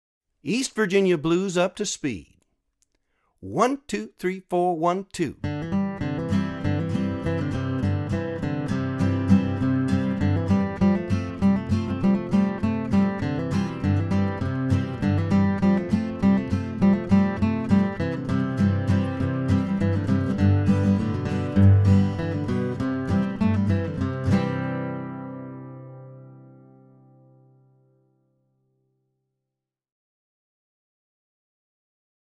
DIGITAL SHEET MUSIC - FLATPICK GUITAR SOLO
(both slow and regular speed)